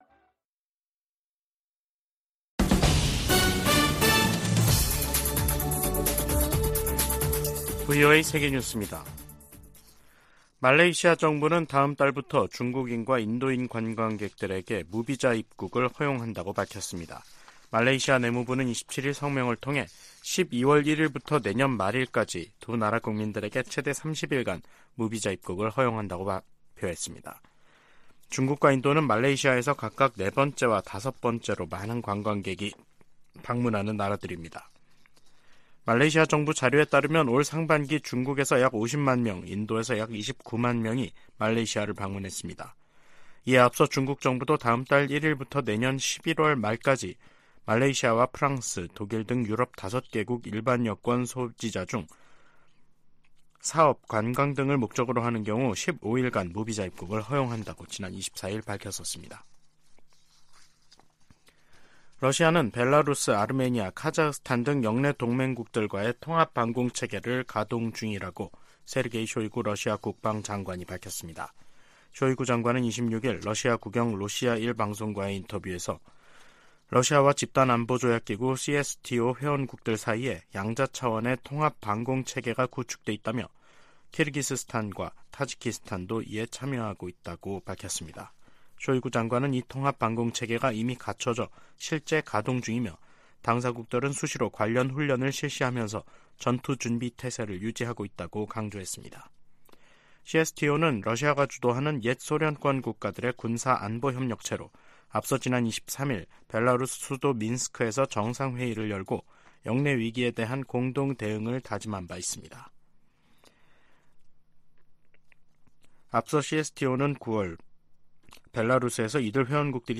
VOA 한국어 간판 뉴스 프로그램 '뉴스 투데이', 2023년 11월 27일 3부 방송입니다. 한국 군 당국은 북한 군이 9.19 남북 군사합의에 따라 파괴한 비무장지대 내 감시초소에 병력과 장비를 다시 투입하고 감시소를 설치 중인 것으로 드러났다고 밝혔습니다. 유엔 안전보장이사회가 북한 위성 발사 대응 긴급회의를 엽니다. 북한과 러시아가 군사적 밀착을 가속화하는 가운데 다른 분야로 협력을 확대하고 있다고 전문가들은 평가하고 있습니다.